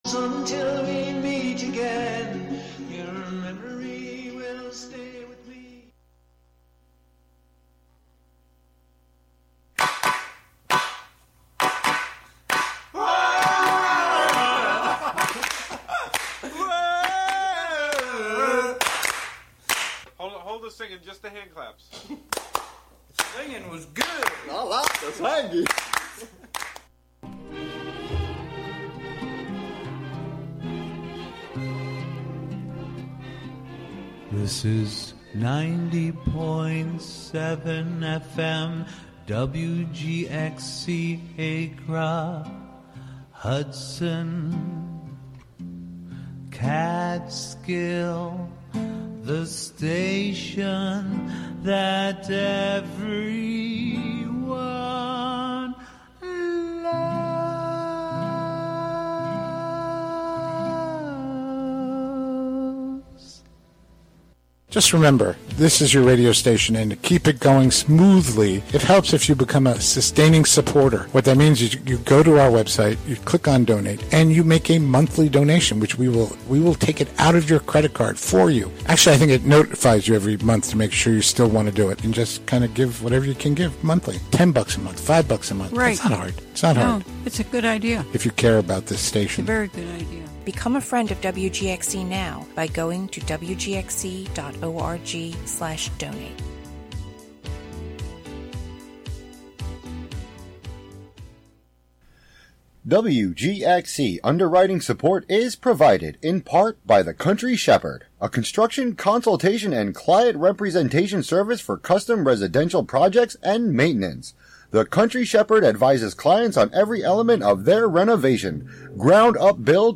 "Long Pause" is an invitation to slow down into interstitial realms through sound. With a blend of song, field recording, archival audio, and conversation, the show explores the thin spaces between the ordinary and sacred, human and nonhuman, particular and universal, and visible and invisible, through a different sonic theme and/or medium each month.